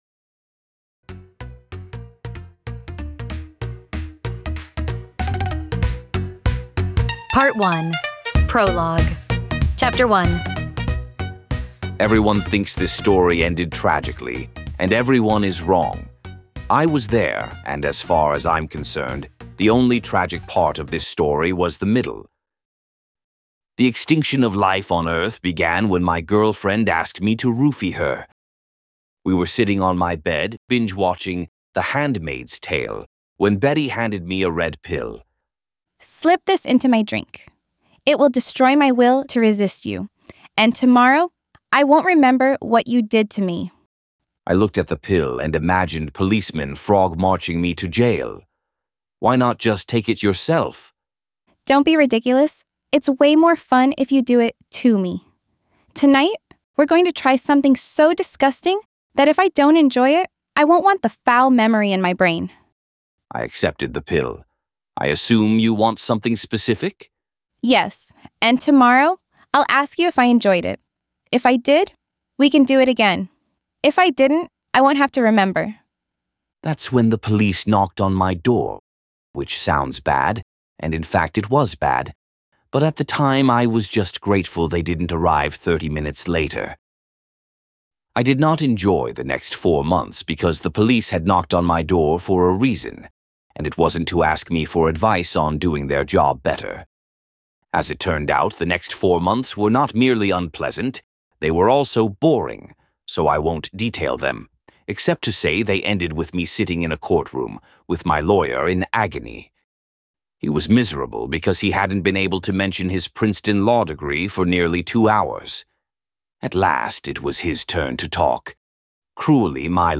Human or AI, you've never heard audiobook narration like this.